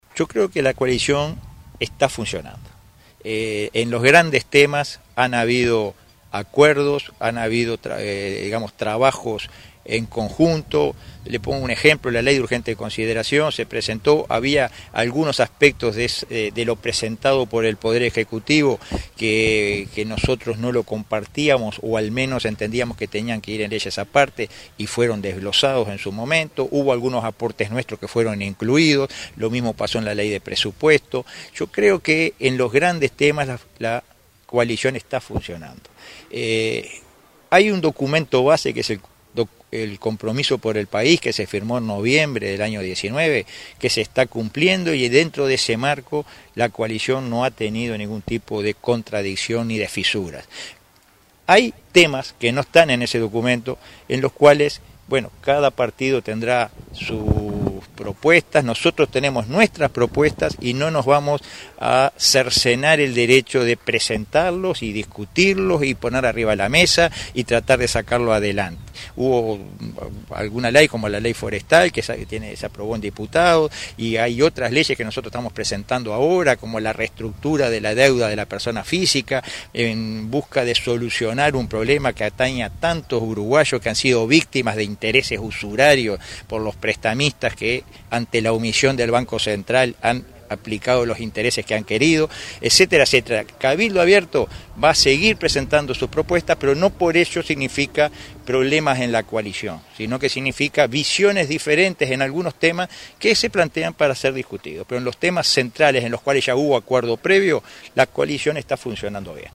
En una recorrida por San José, dijo en rueda de prensa que los anuncios del presidente Luis Lacalle Pou en la Asamble General, «en ciertos aspectos tocaron temas que nosotros habiamos planteado, tenemos una seria preocupación por el trabajo nacional: en las pequeñas, micro y medianas empresas que apuestan al Uruguay y quiere progresar».